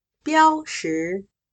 标识/Biāozhì/Indicar, marcar, simbolizar./Zhì/Recordar, memorizar.